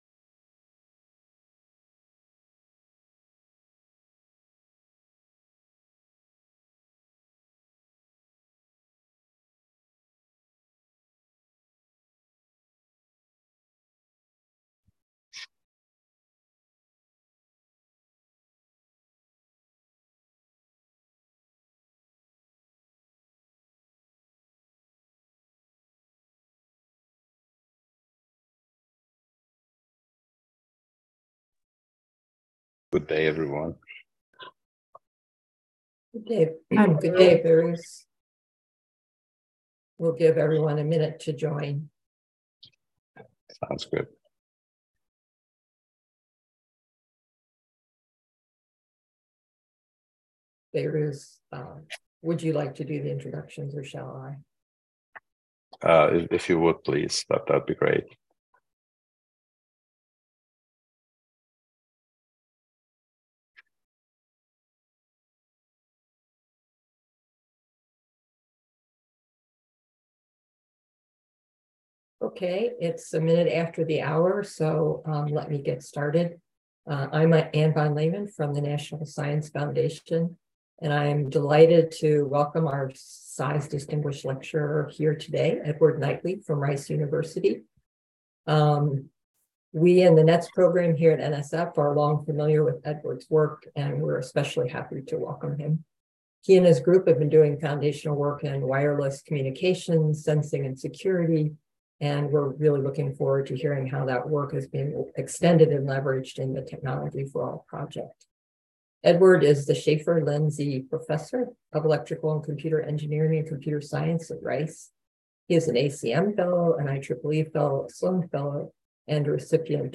CISE Distinguished Lecture Series